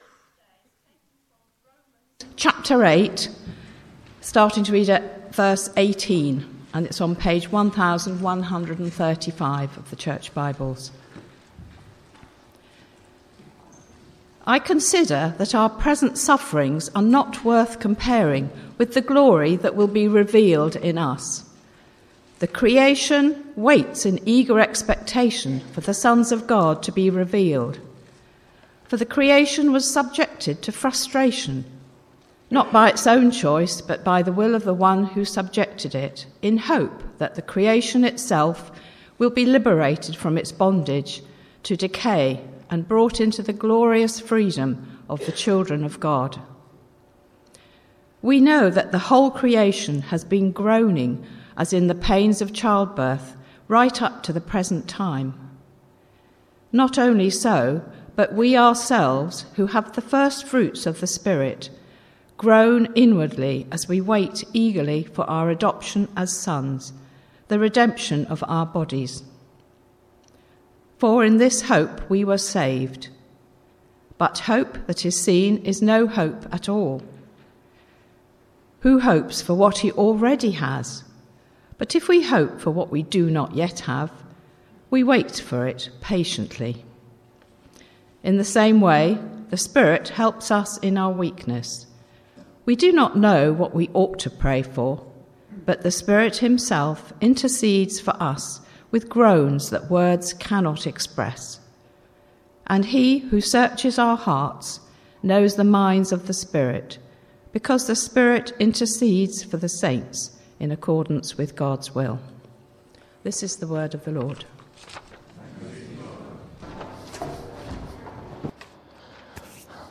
Romans 8:18-27 Service Type: Sunday Morning « Spiritually Healthy?